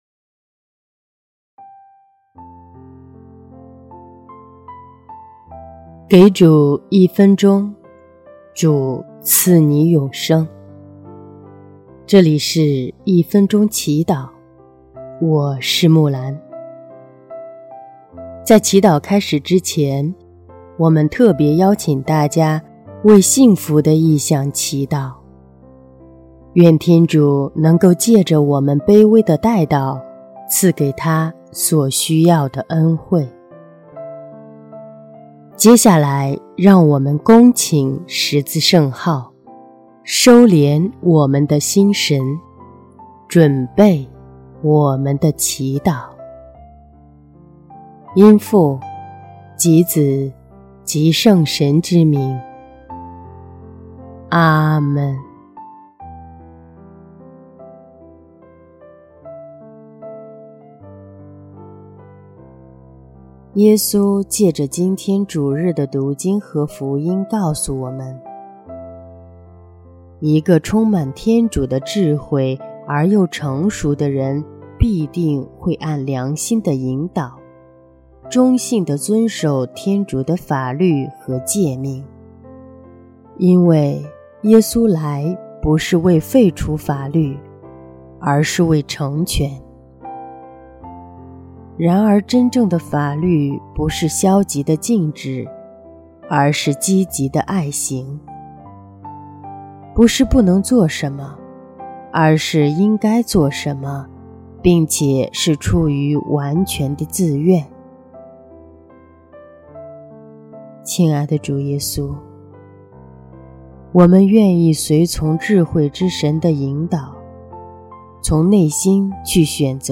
【一分钟祈祷】| 2月12日 主，请赐给我所需要的恩宠和力量，勇敢的去履行祢的诫命
音乐：第二届华语圣歌大赛参赛歌曲《你来跟随我》